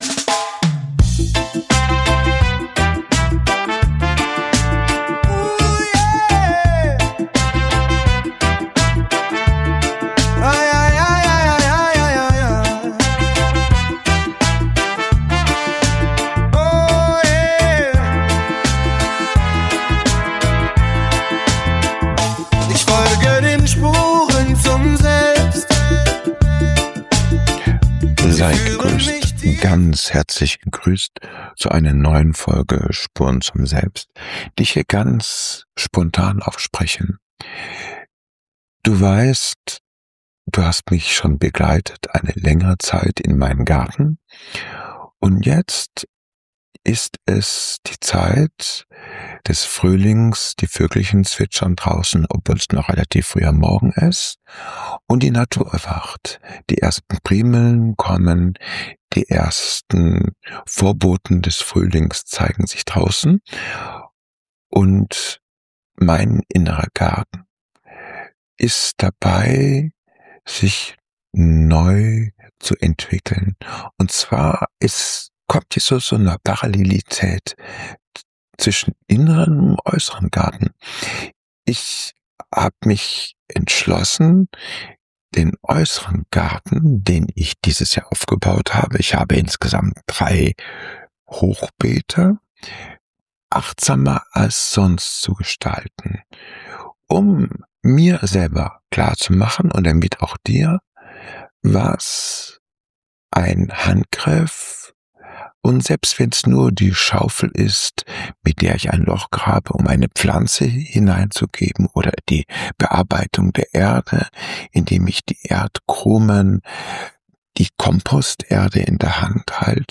Beschreibung vor 4 Wochen In dieser Episode von "Spuren zum Selbst" lade ich dich ein, mit mir in meinen Garten zu reisen, während die ersten Anzeichen des Frühlings erwachen. Der Gesang der Vögel und das Sprießen der Primeln sind für mich der perfekte Hintergrund, um über die Parallelen zwischen der Natur und meinem inneren Wandel nachzudenken.